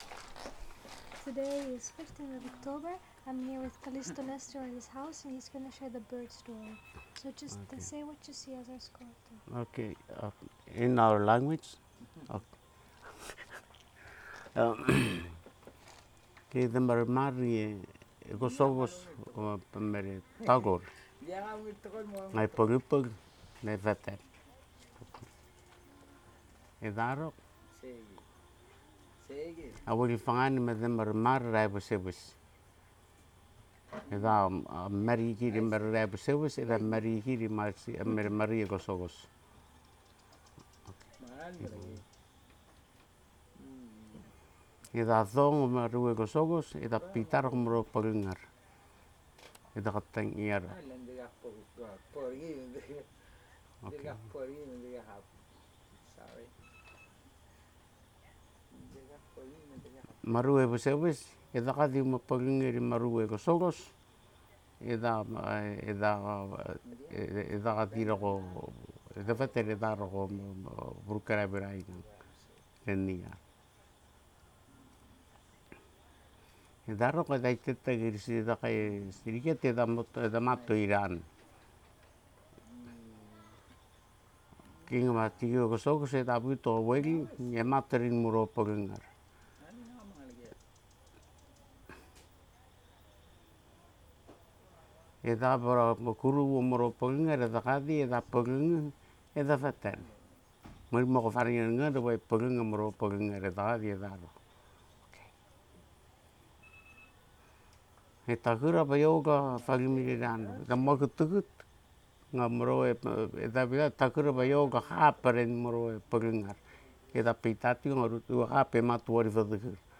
digital wav file recorded at 44.1 kHz/16 bit on Zoom H2N
Echang, Koror, Palau